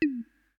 window-minimized.ogg